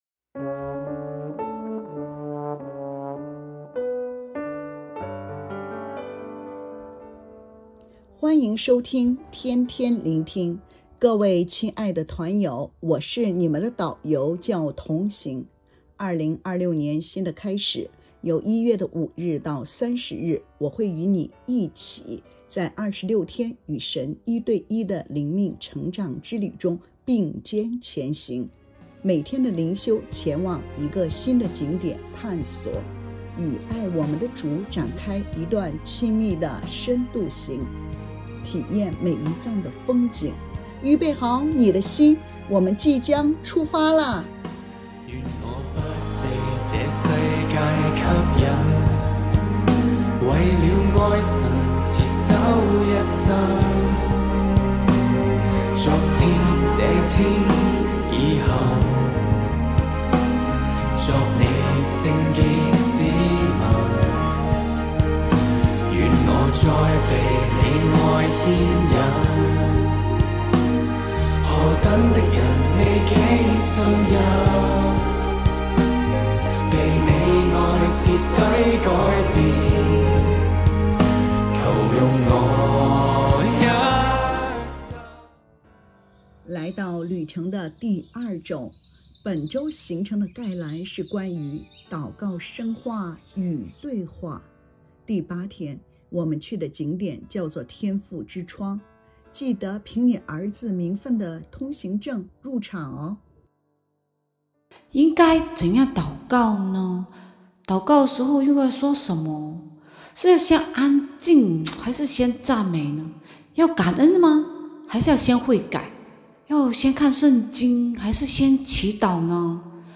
🎶靈修詩歌：《我是祢愛子》玻璃海樂團